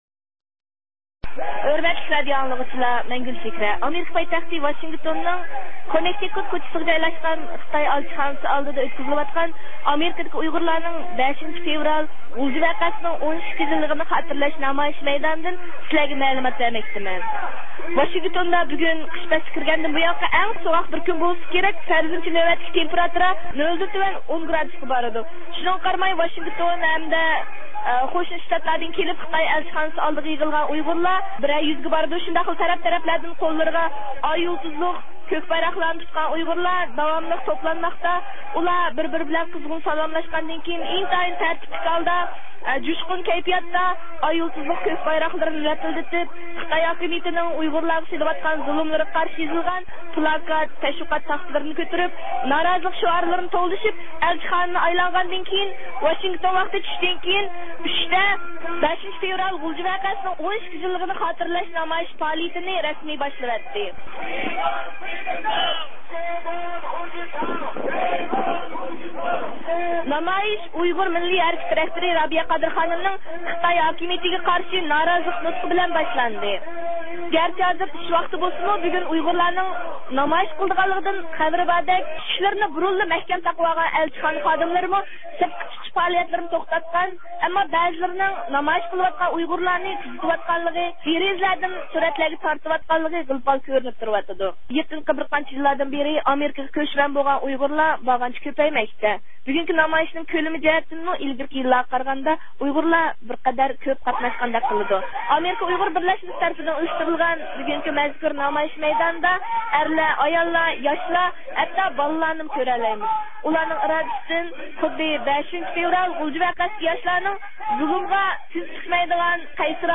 ۋاشىنگتوندا 5 – فېۋرال غۇلجا ۋەقەسىنىڭ 12 يىللىق خاتىرە نامايىشى – ئۇيغۇر مىللى ھەركىتى